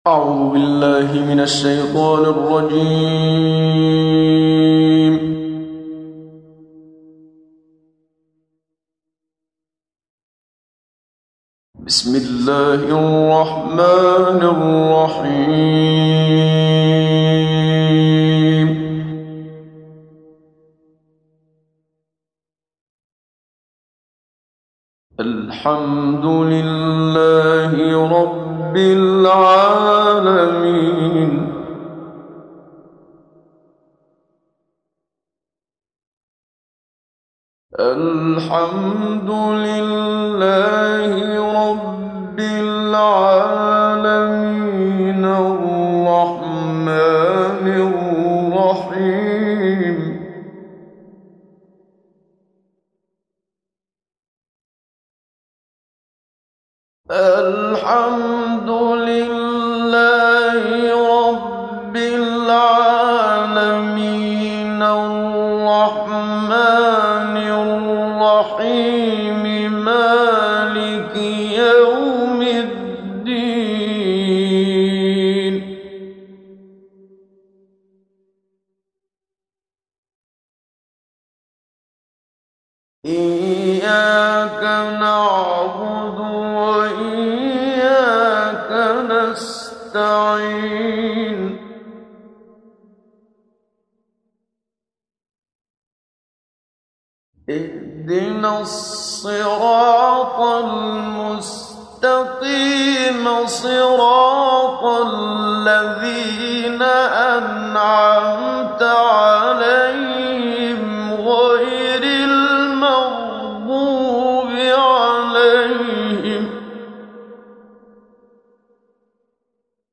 محمد صديق المنشاوي – تجويد